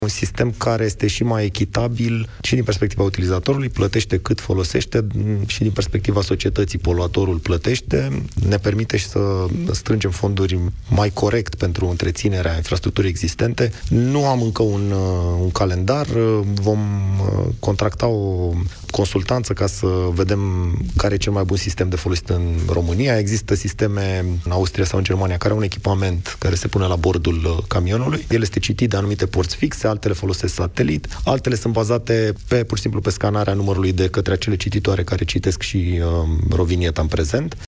Ministrul Transporturilor, Cătălin Drulă vine cu precizări la Europa FM în emisiunea Deșteptarea României, după ce ministrul fondurilor europene a anuntat că transportatorii de mărfuri pe drumurile naționale vor fi taxati la numărul de kilometri parcuși, nu pe zi.